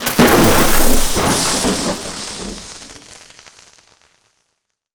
pgs/Assets/Audio/Magic_Spells/elec_lightning_magic_spell_02.wav at master
elec_lightning_magic_spell_02.wav